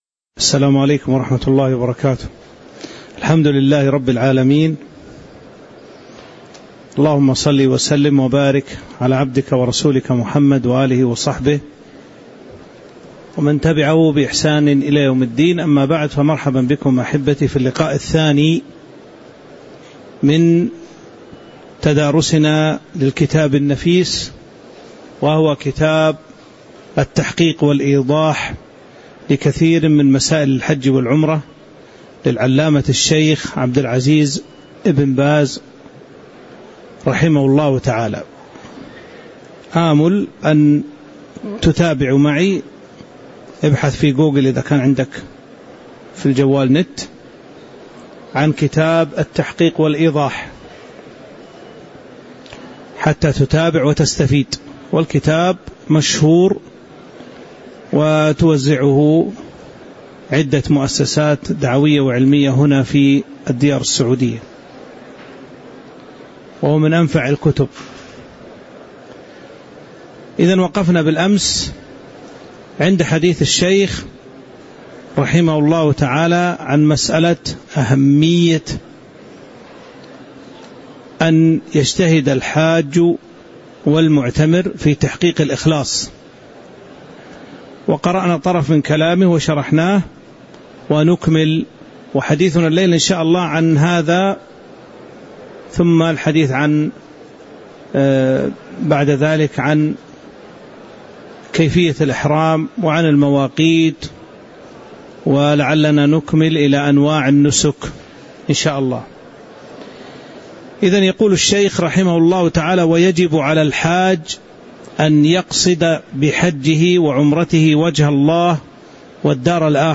تاريخ النشر ٢١ ذو القعدة ١٤٤٦ هـ المكان: المسجد النبوي الشيخ